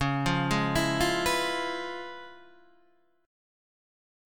Db7#9 Chord